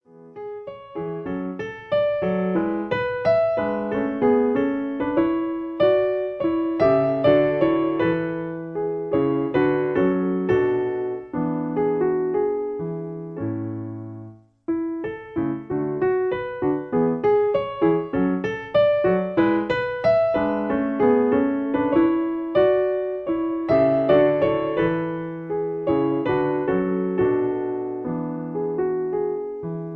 Piano accompaniment. In A